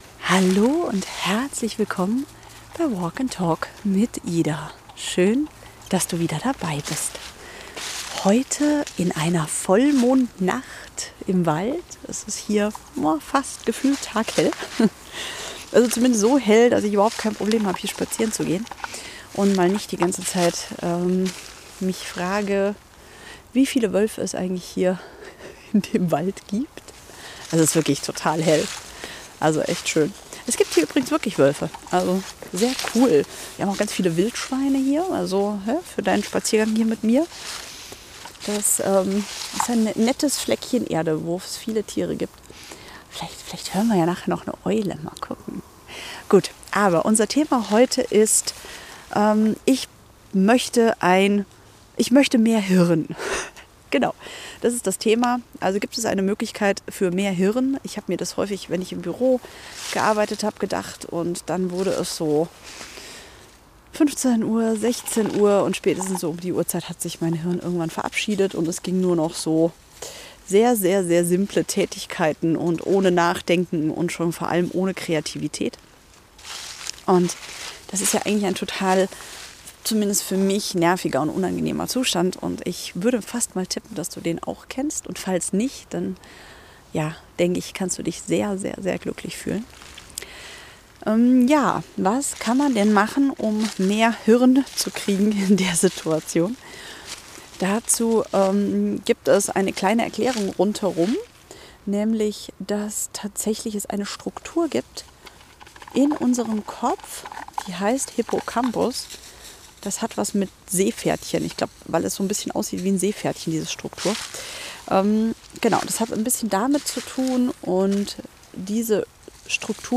Genau das, was wir gerade machen: spazieren gehen.